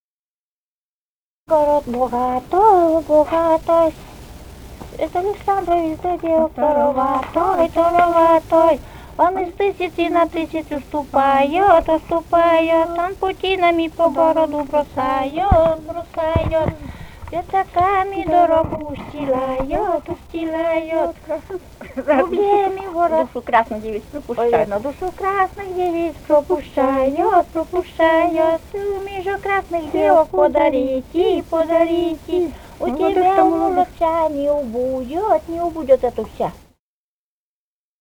«Тысяцкий богатый» (свадебная).